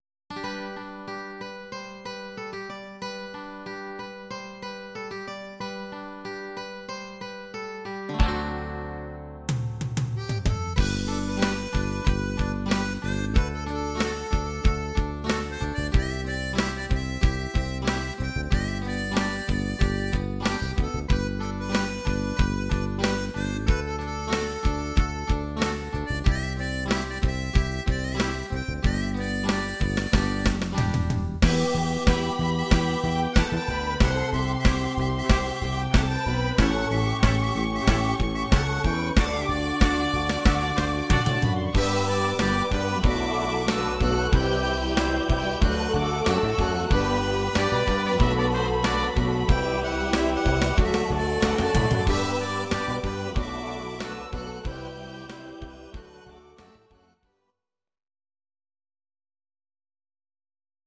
Audio Recordings based on Midi-files
Pop, 1970s